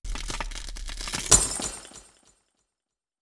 MIRROR_SHATTER.wav